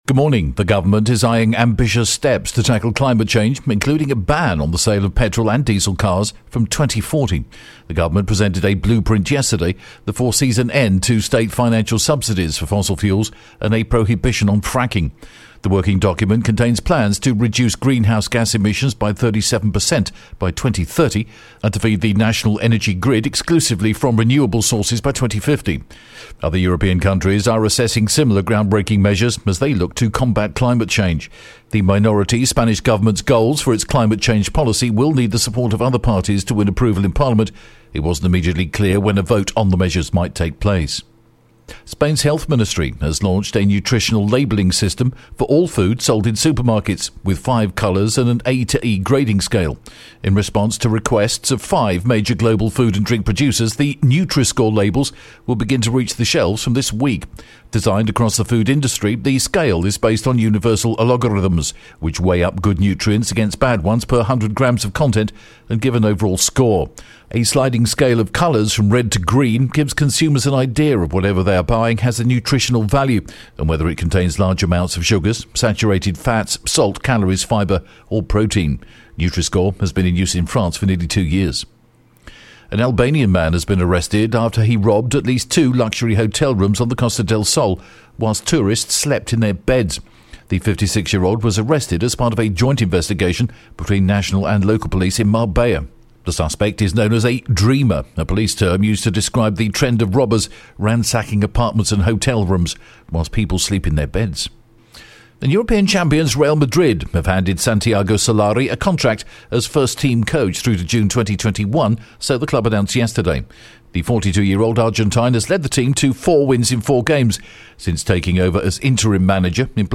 The latest Spanish News Headlines in English: November 14th